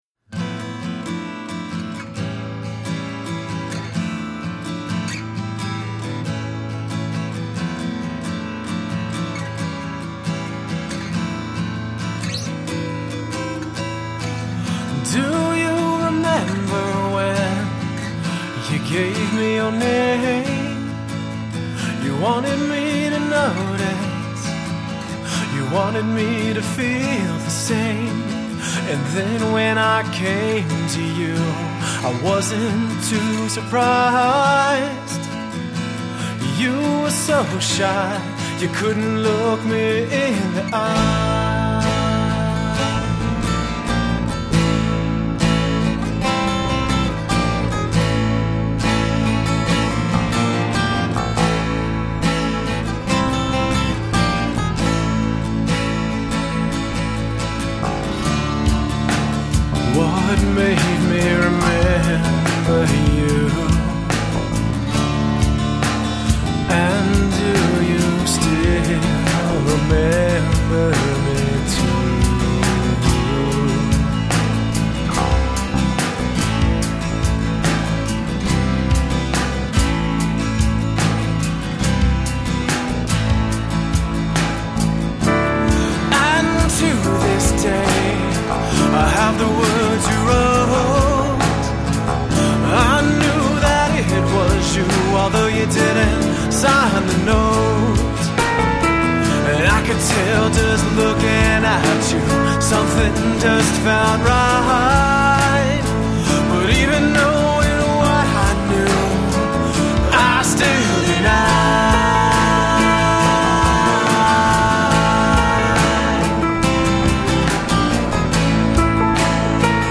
Guitar, Keyboards, Vocals
Drums
Piano
Bass Guitar